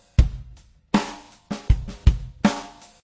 rhythm_2.ogg